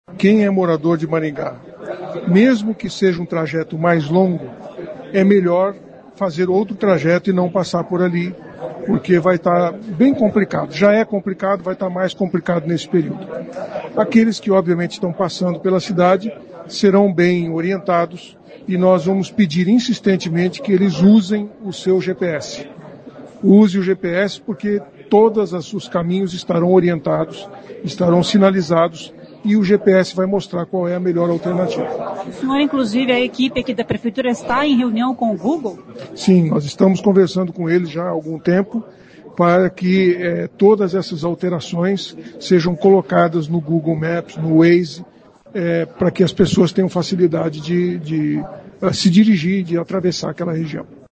A intervenção é drástica e apesar de toda a sinalização e orientação de agentes de trânsito, o prefeito Silvio Barros disse que o melhor é que os motoristas evitem aquela região.